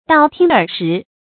道聽耳食 注音： ㄉㄠˋ ㄊㄧㄥ ㄦˇ ㄕㄧˊ 讀音讀法： 意思解釋： 對傳聞之辭不加去取，盲目輕信。